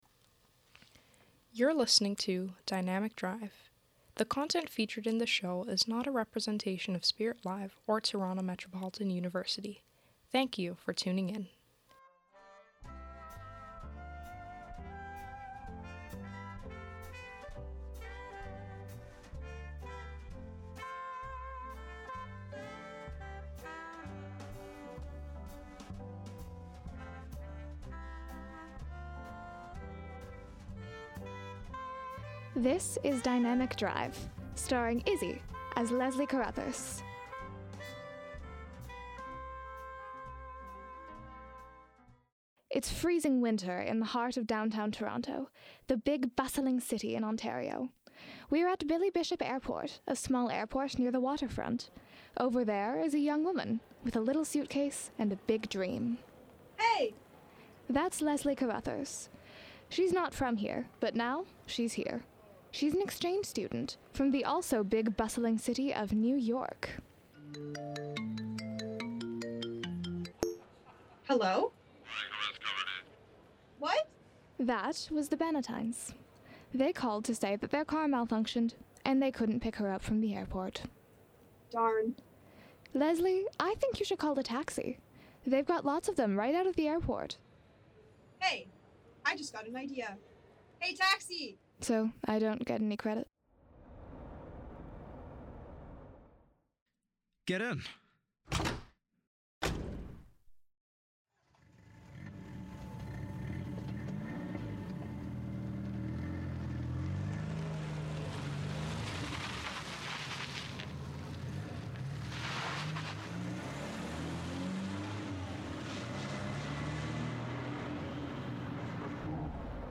Dynamic Drive is a radio comedy series centred around Leslie Caruthers, a New York exchange student who moves to Toronto with the Bannatyne family.